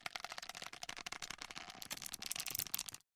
На этой странице собрана коллекция звуков кулаков — резкие удары, глухие толчки, звонкие столкновения.
3. Вариант со сжатием кулаков или головы